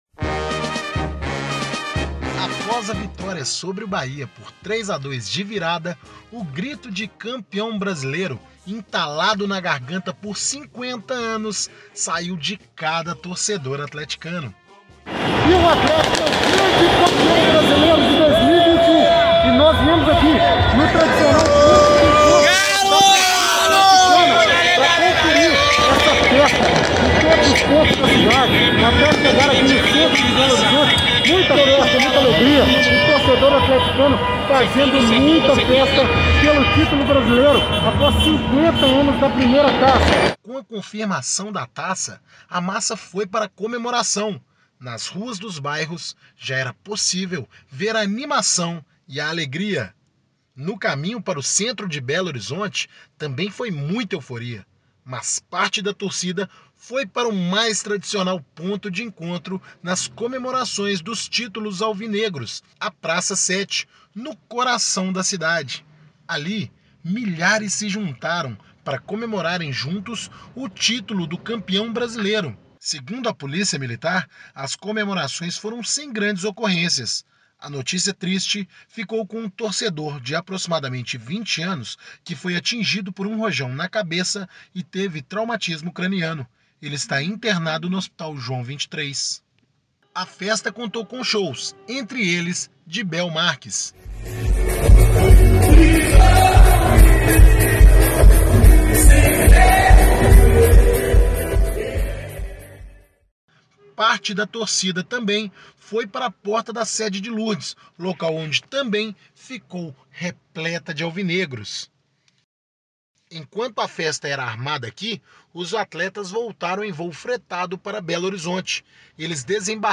As comemorações do título de Campeão Brasileiro, conquistado após 50 anos, passaram da madrugada e os atleticanos amanheceram o dia na Praça Sete.